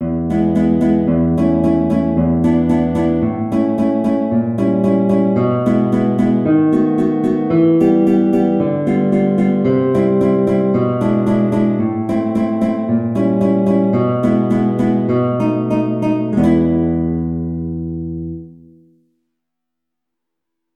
2/2 (View more 2/2 Music)
E3-G5
E major (Sounding Pitch) (View more E major Music for Guitar )
Guitar  (View more Easy Guitar Music)
Classical (View more Classical Guitar Music)